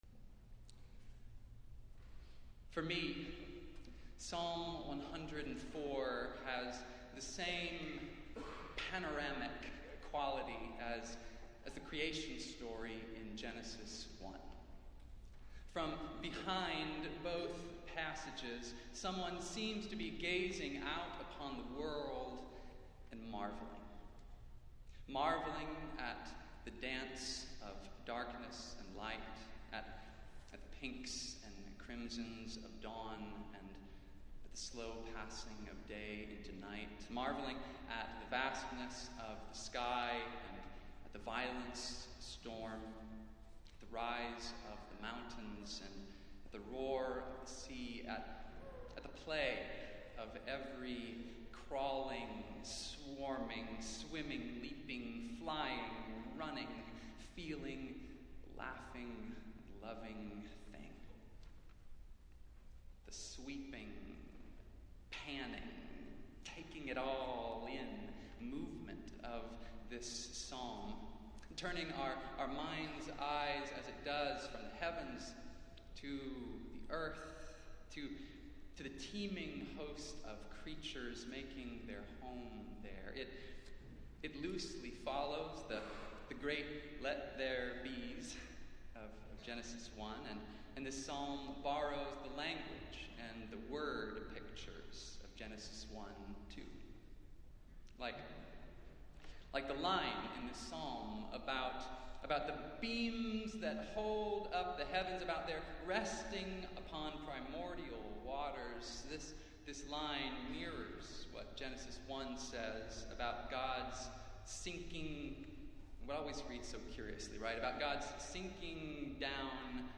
Festival Worship - World Communion Sunday